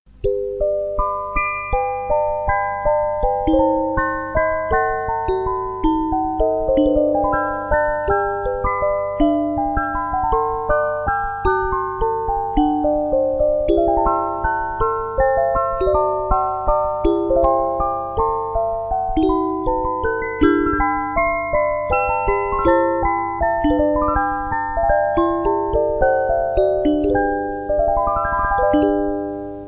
Spieldose „Ein schönes Leben“(Junge)